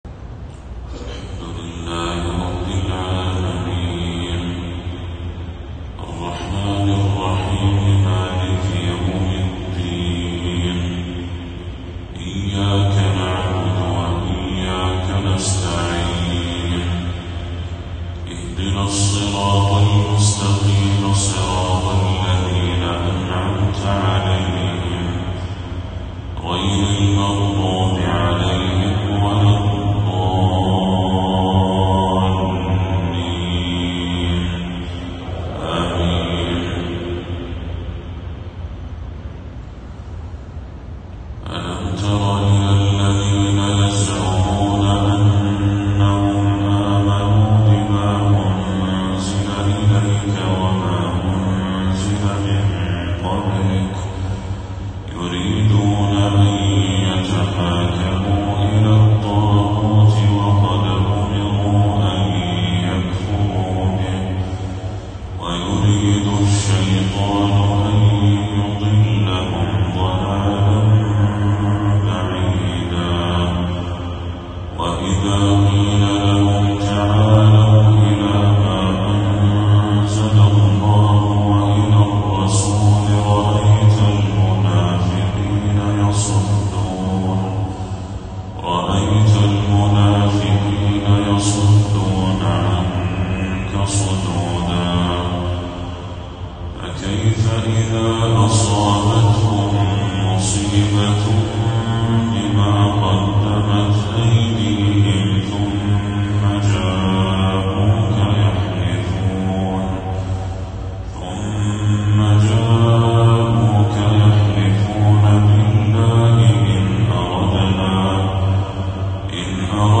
تلاوة من سورة النساء للشيخ بدر التركي | فجر 10 صفر 1446هـ > 1446هـ > تلاوات الشيخ بدر التركي > المزيد - تلاوات الحرمين